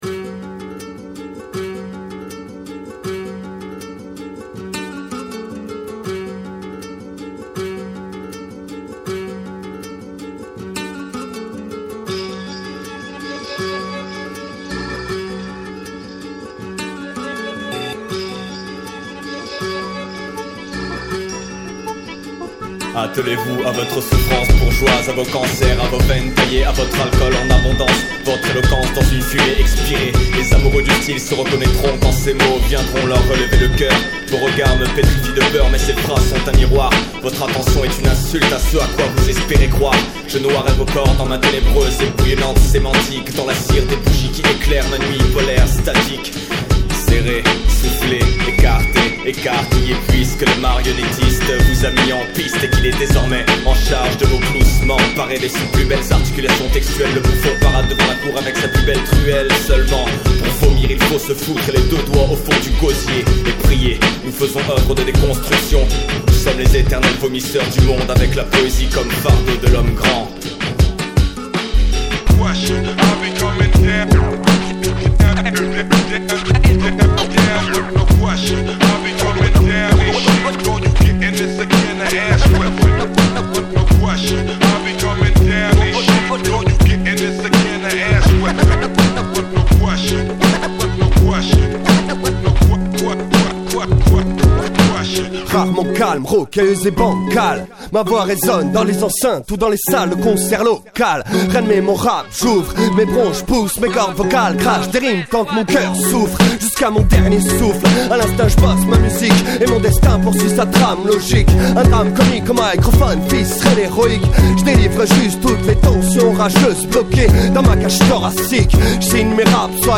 22 beats